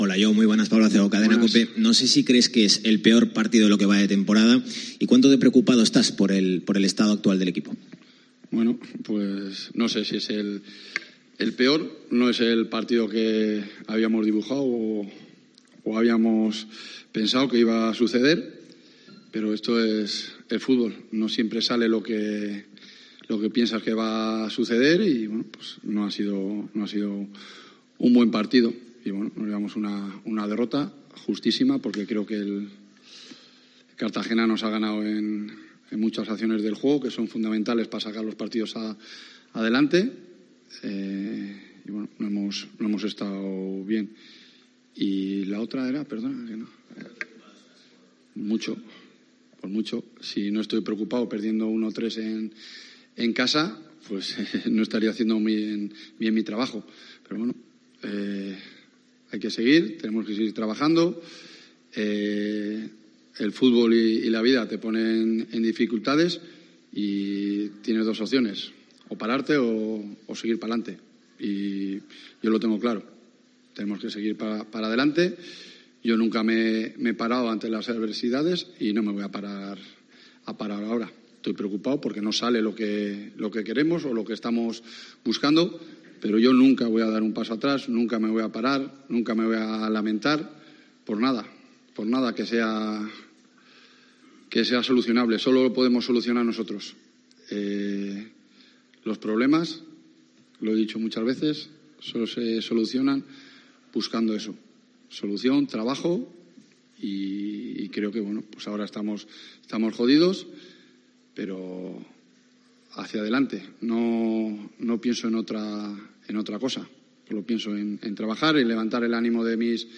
Rueda de prensa Bolo (post Cartagena)